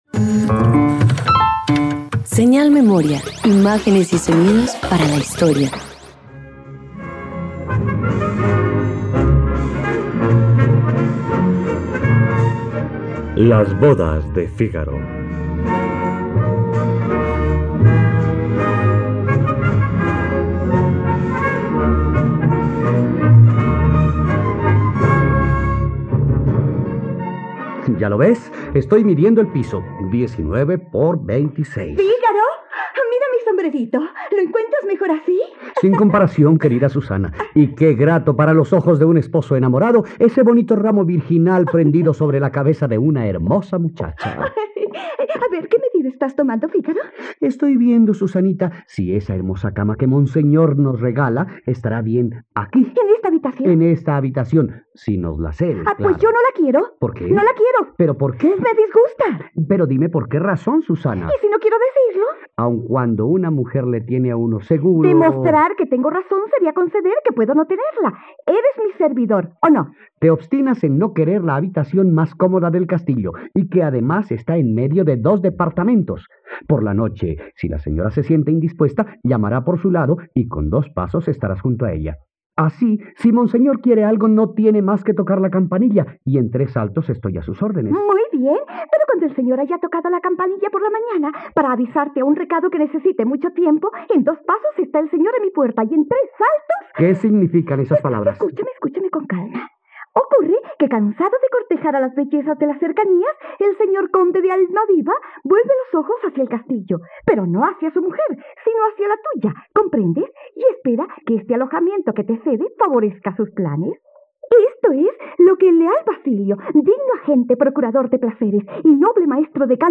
..Radioteatro. Disfruta la versión radiofónica de ‘Las bodas de Fígaro ’de Beaumarchais en la plataforma de streaming de todos los colombianos: RTVCPlay.
radioteatro